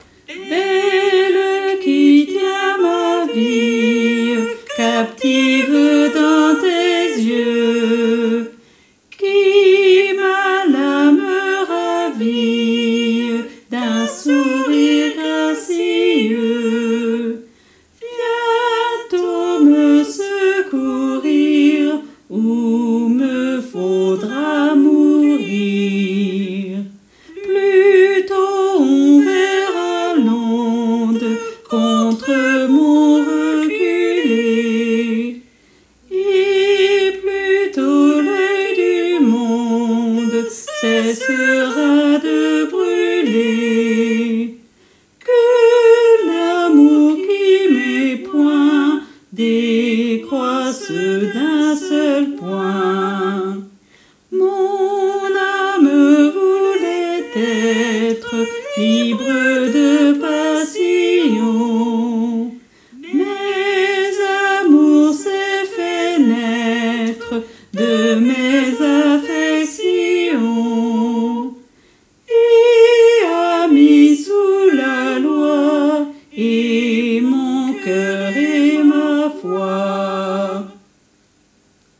Hommes et autres voix en arrière-plan